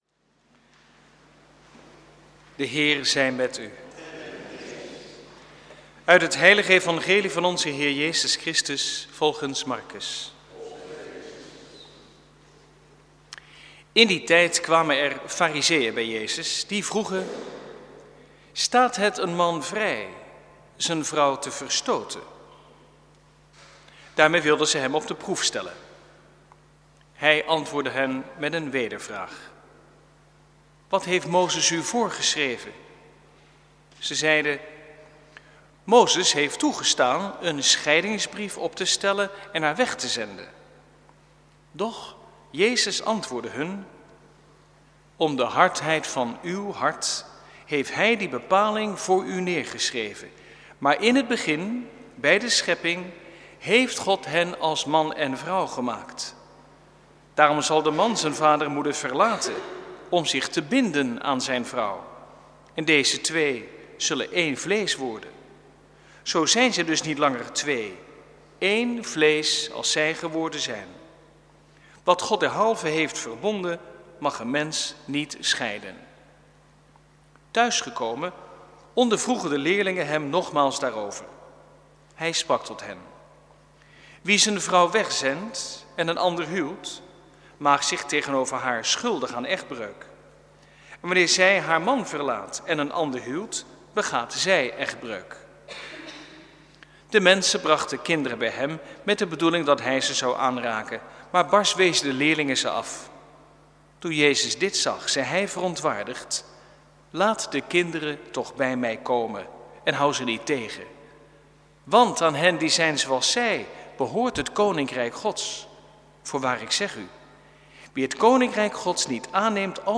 Preek 27e zondag, door het jaar B, 6/7 oktober 2012 | Hagenpreken
Eucharistieviering beluisteren vanuit de Willibrordkerk te Oegstgeest (MP3)